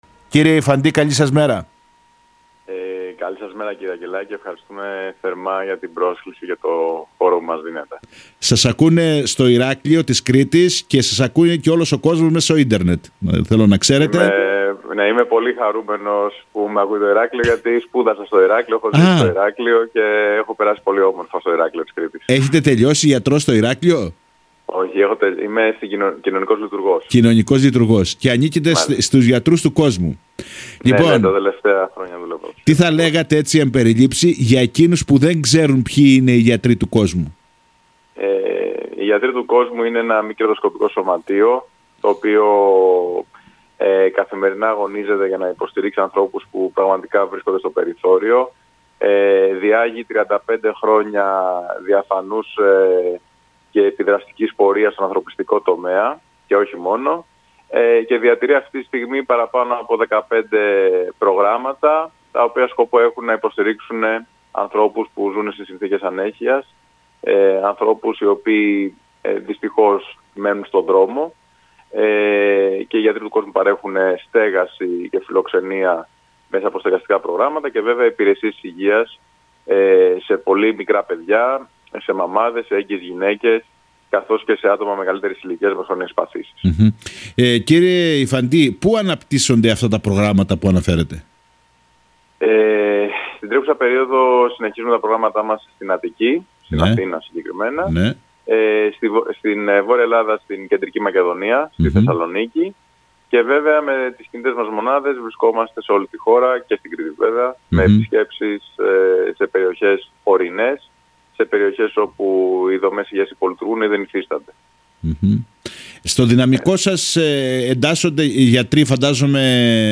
Με λόγο άμεσο και ανθρώπινο